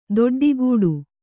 ♪ doḍḍigūḍu